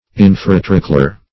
Search Result for " infratrochlear" : The Collaborative International Dictionary of English v.0.48: Infratrochlear \In`fra*troch"le*ar\, a. [Infra + trochlear.]